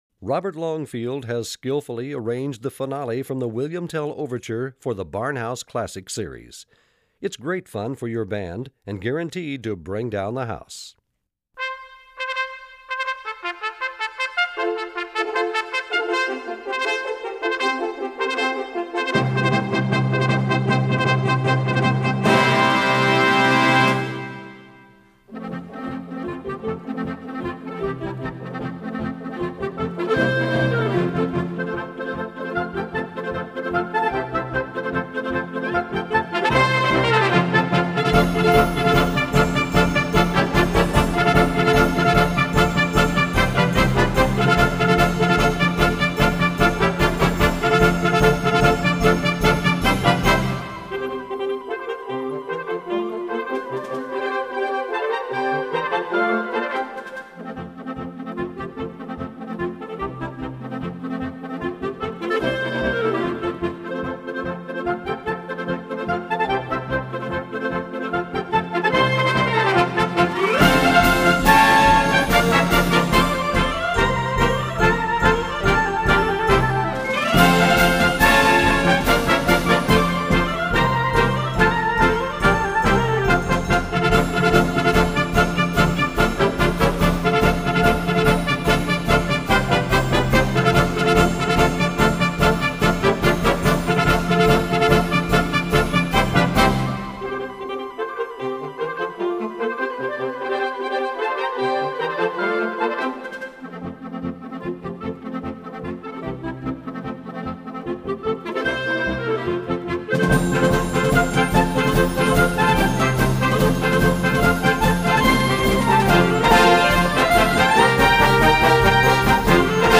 Gattung: Ouverture
Besetzung: Blasorchester